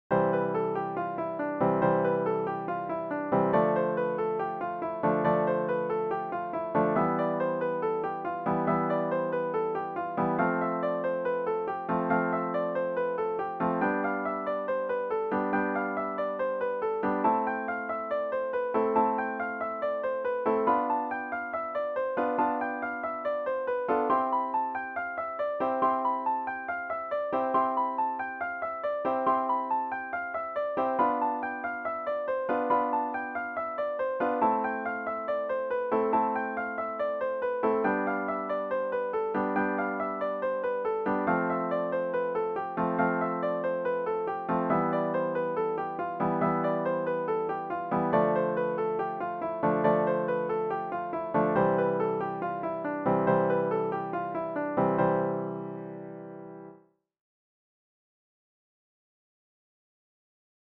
🎹 Piano Technique Exercise